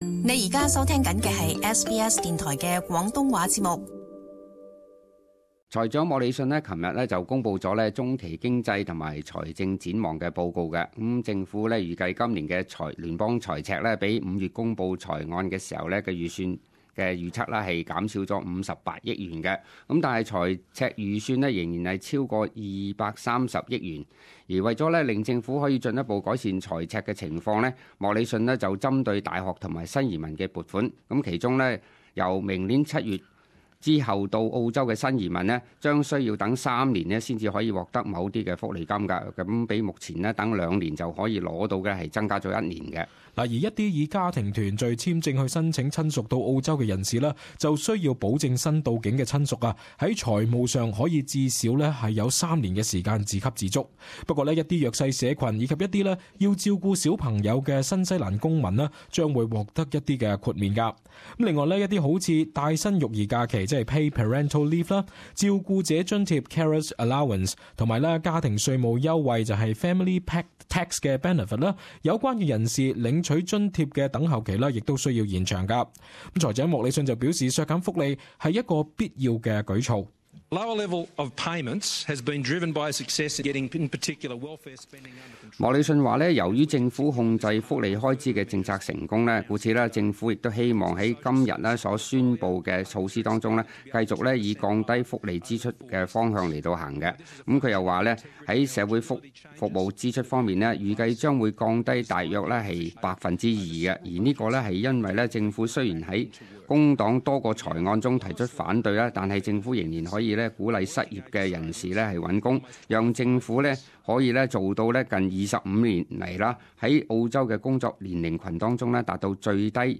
【時事報道】澳洲移民福利金等候期增加一年